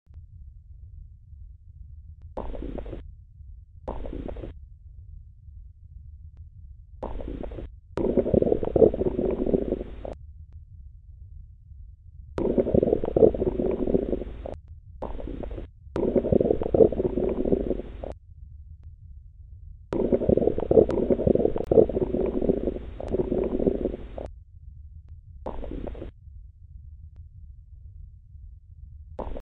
Auscultación
hiperperistaltismo (peristaltismo = motilidad intestinal se manifiesta por ruidos hidroaéreos) puede sugerir obstrucción y su ausencia durante más de cinco minutos sugiere peritonitis.
Hyperactive_bowel_sounds.mp3